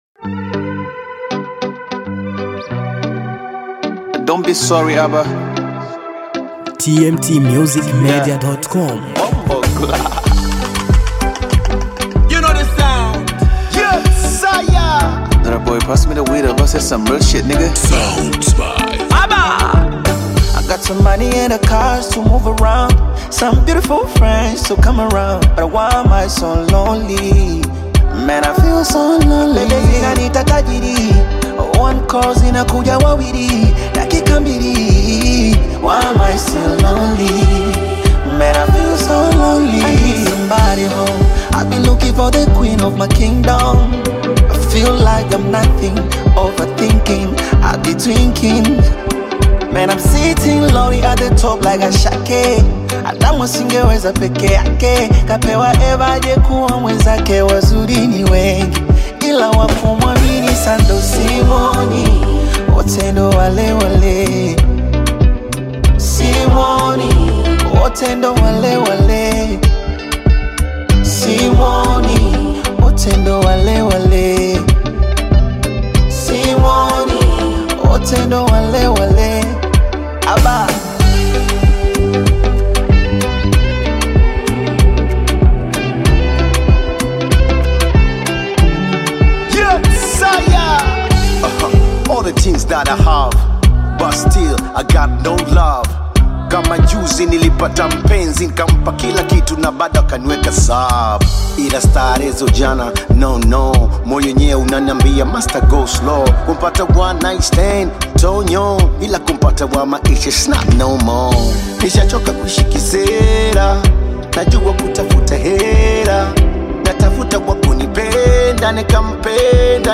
a fresh Bongo Flava anthem packed with rhythm and emotion.